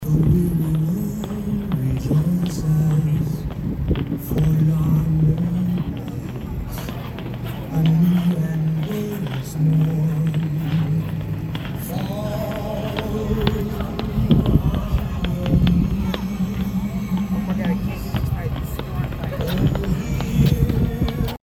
The evening air was filled with holiday music and the rink was packed with skaters getting their first chance to try out the new holiday amenity after months of anticipation.
skating-nat-sound.mp3